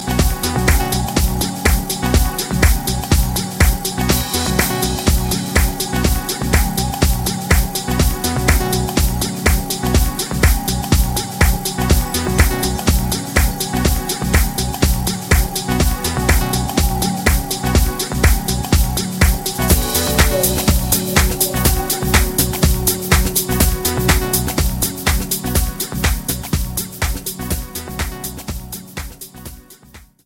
Inst